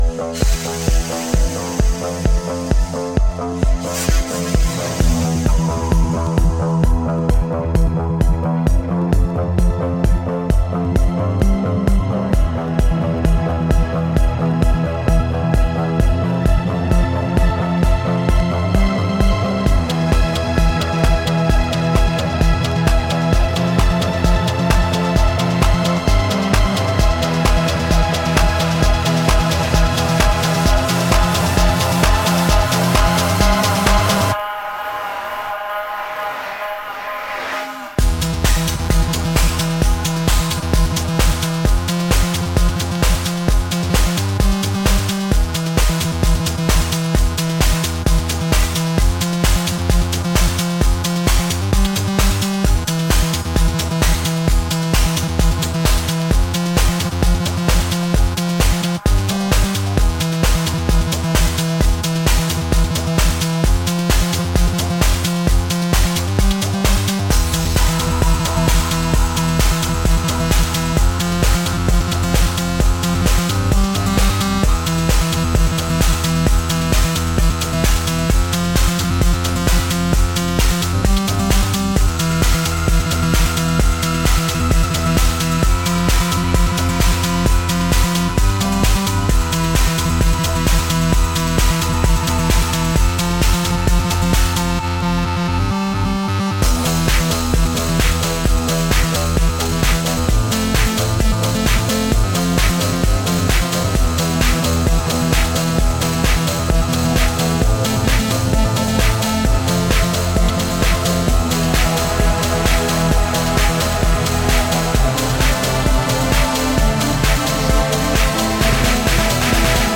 House/Tech House, Trance